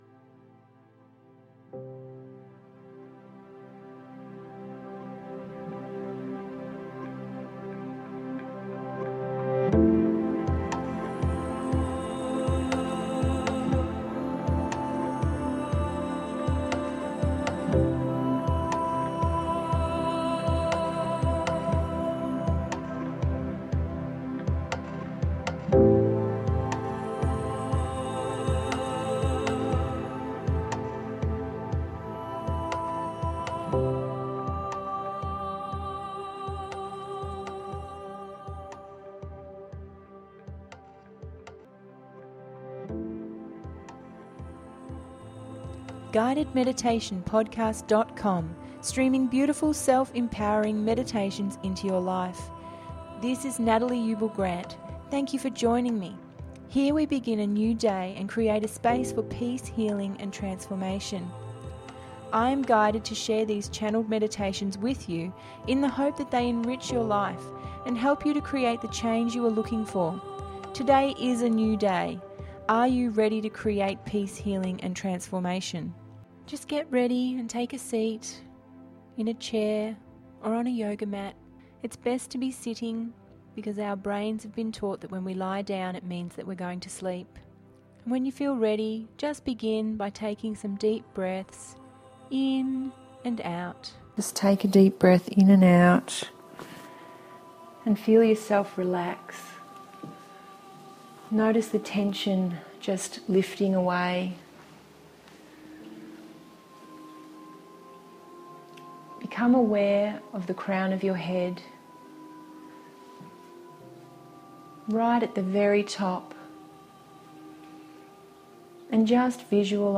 Release And Manifest…066 – GUIDED MEDITATION PODCAST